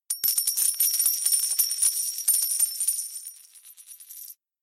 moneyLost.ogg